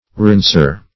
rinser - definition of rinser - synonyms, pronunciation, spelling from Free Dictionary Search Result for " rinser" : The Collaborative International Dictionary of English v.0.48: Rinser \Rins"er\, n. One who, or that which, rinses.